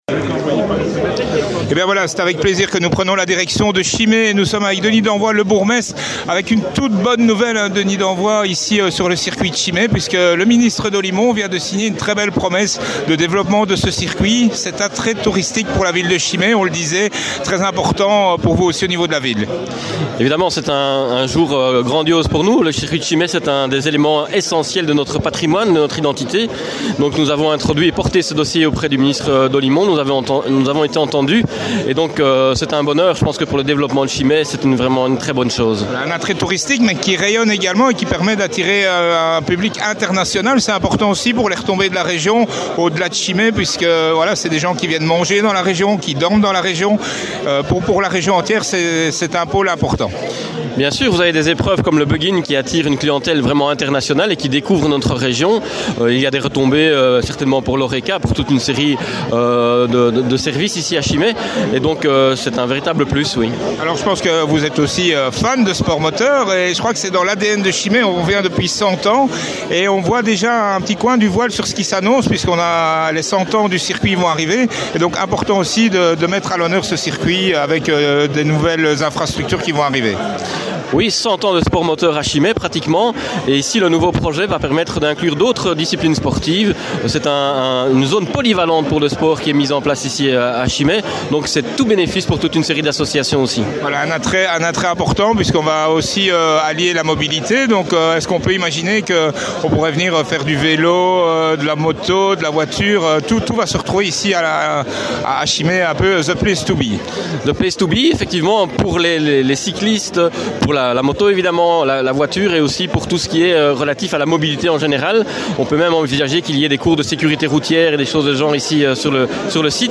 Mr Denis Danvoye, Bourgmestre de Chimay